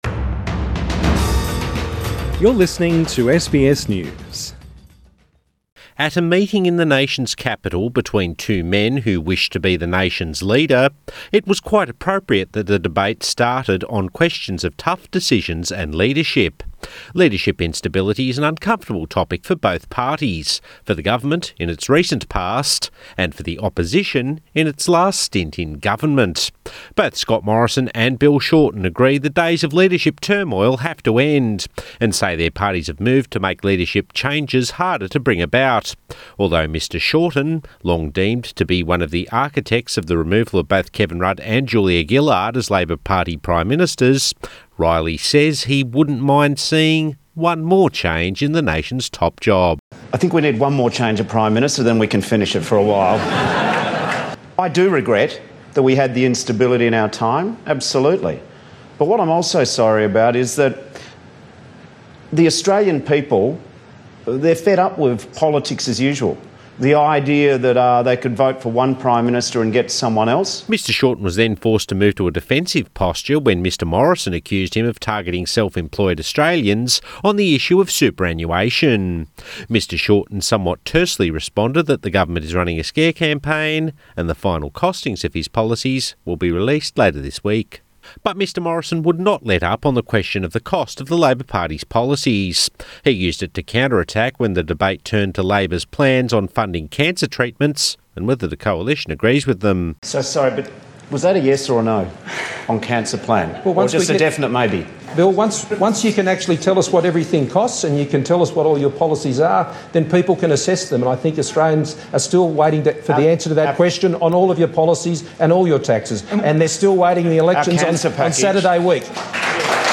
At the National Press Club in Canberra, the two men seeking to be Prime Minister went over plenty of talking points, both old and new.
Bill Shorten and Scott Morrison during the third Leaders Debate Source: AAP